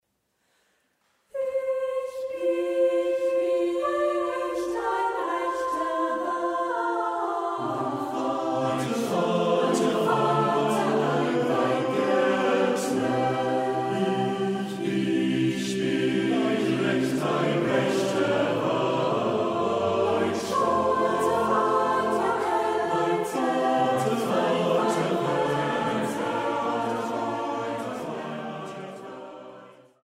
Marienmusik aus dem evangelischen Dom St. Marien zu Wurzen
Orgel